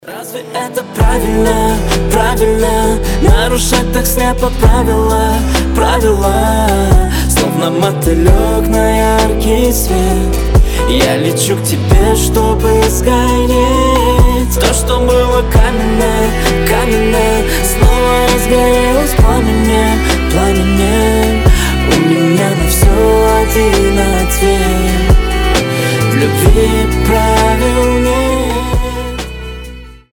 • Качество: 320, Stereo
гитара
дуэт
скрипка